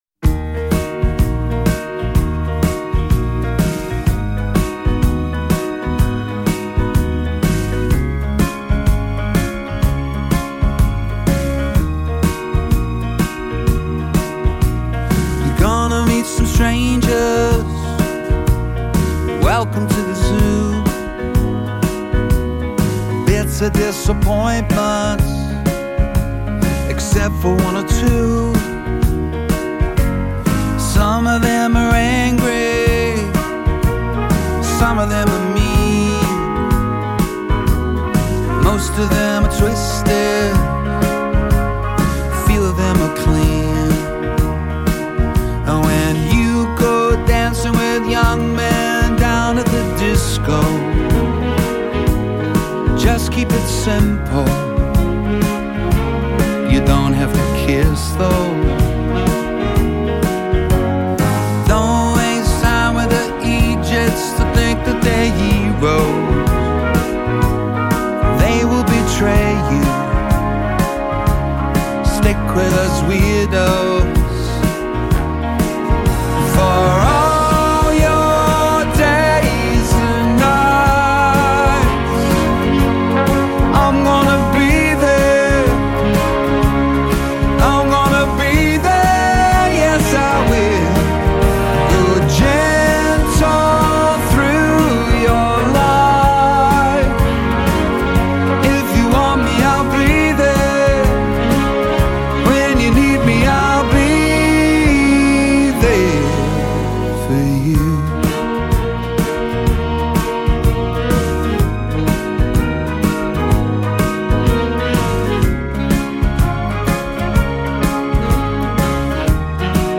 expresses a tender, protective sentiment